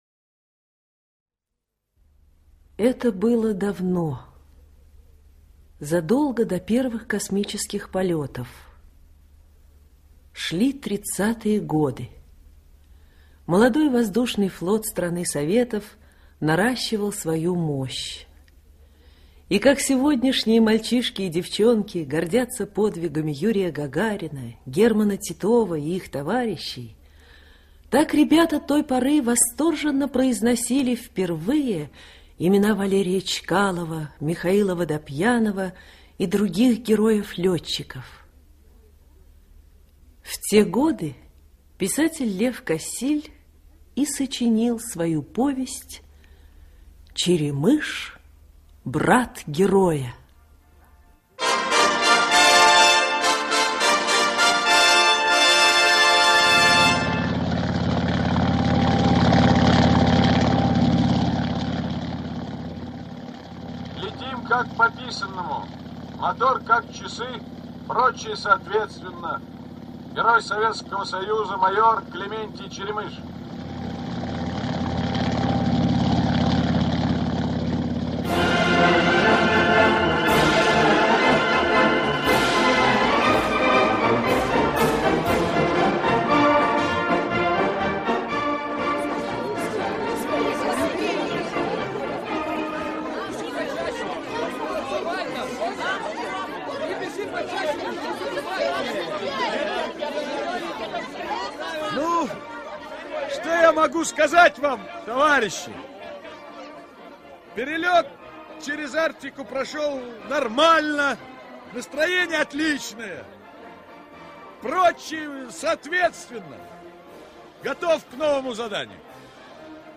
Черемыш – брат героя - аудио повесть Кассиля - слушать